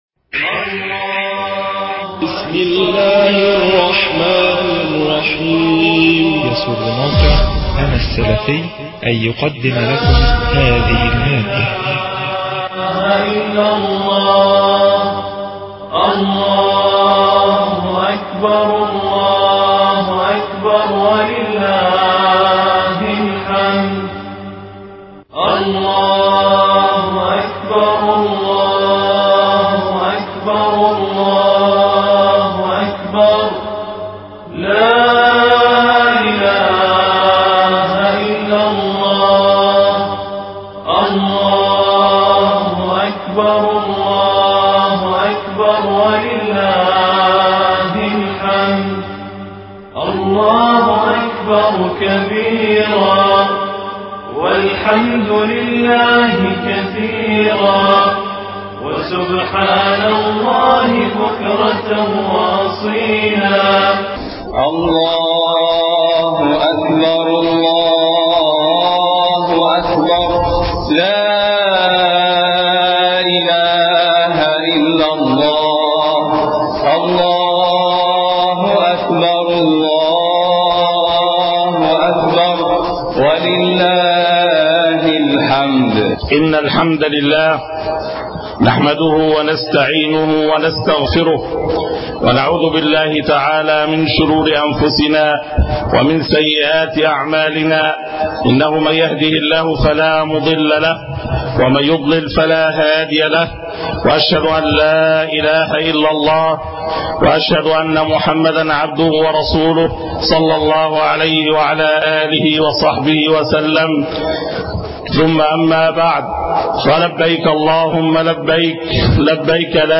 خطبة عيد الأضحى 1432